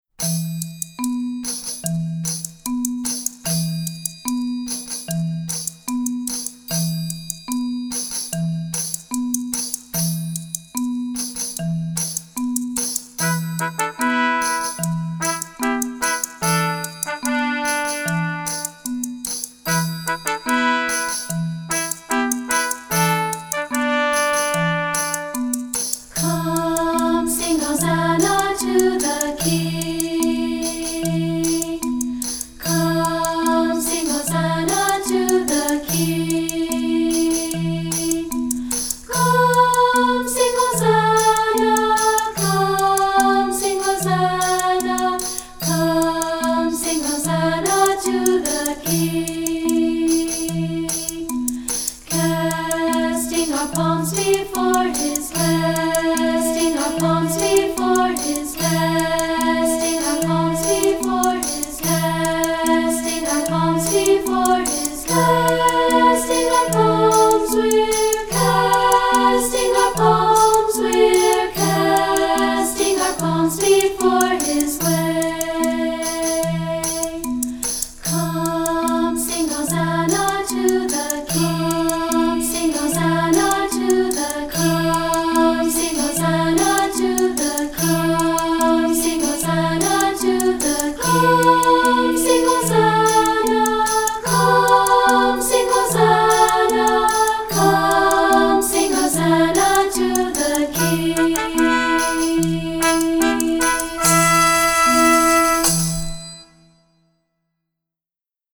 Anthems for Treble Voices
Unison/two-part with Orff instruments and percussion